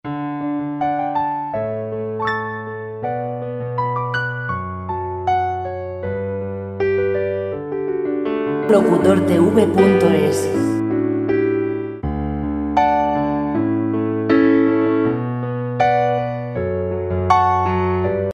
musica clasica sin copyright musica folk libre de derechos